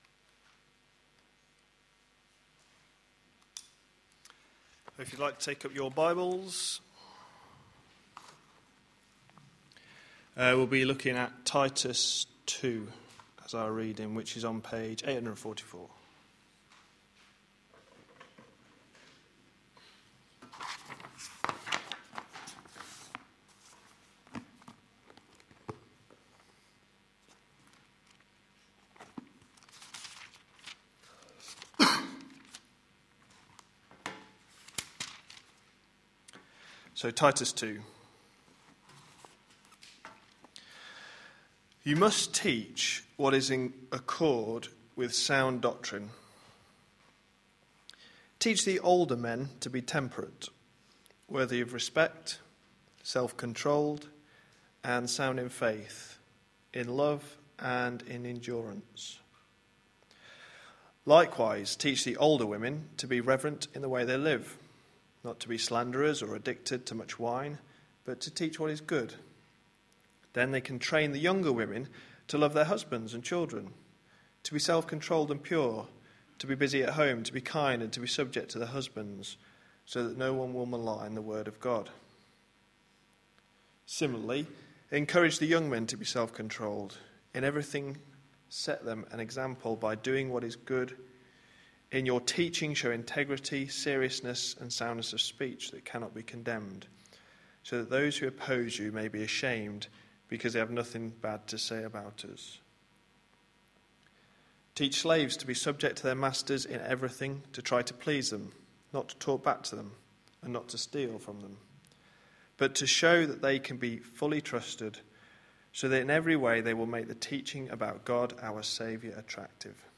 A sermon preached on 3rd June, 2012, as part of our Joining In series.